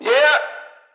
TS_FUTURE_vocal_one_shot_yeah_3.wav